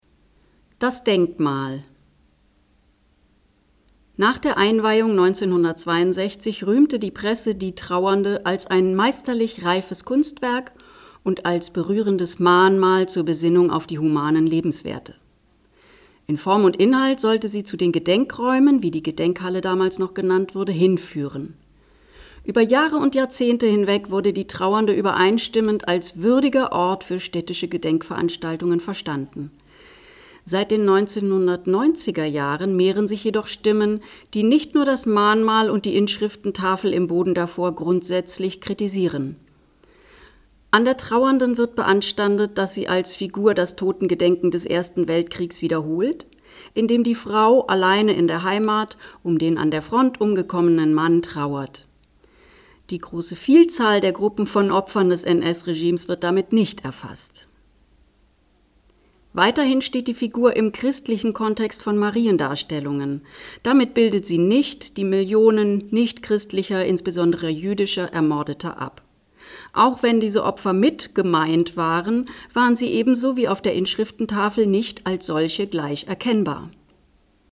Tafeltext vorlesen